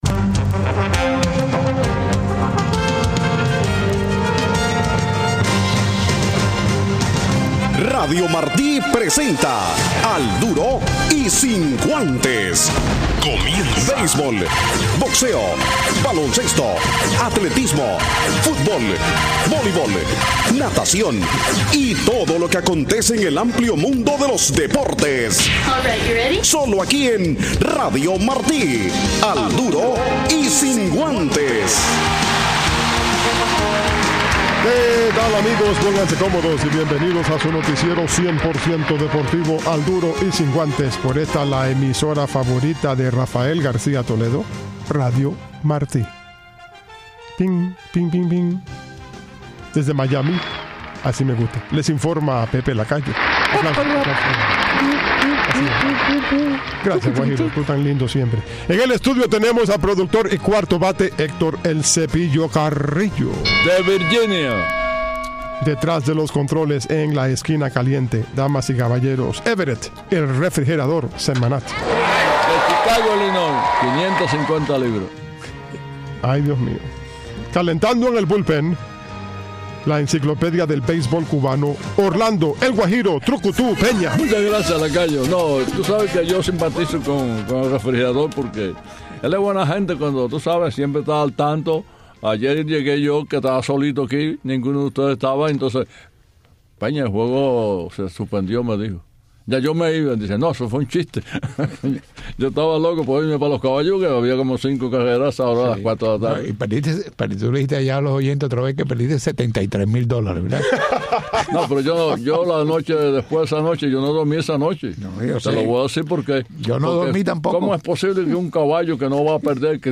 Al Duro del lunes con una entrevista